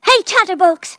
synthetic-wakewords
ovos-tts-plugin-deepponies_Rarity_en.wav